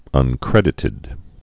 (ŭn-krĕdĭ-tĭd)